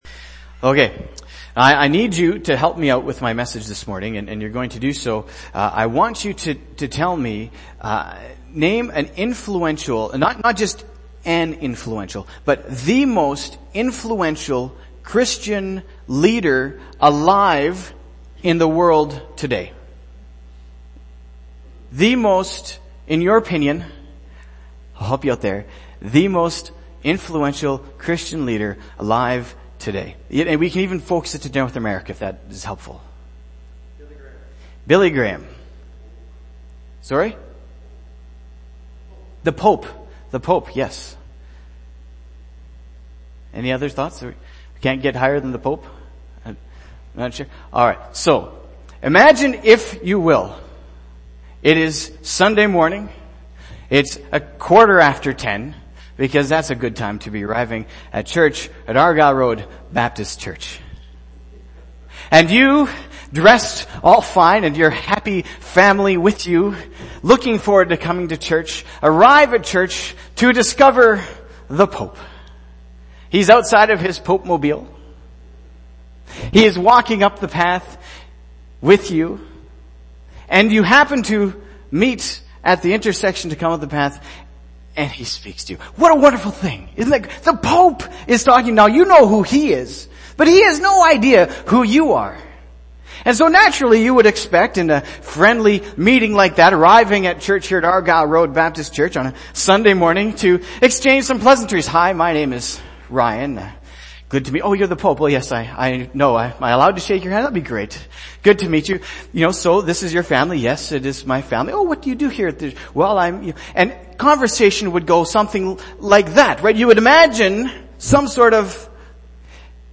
Argyle Road Baptist Church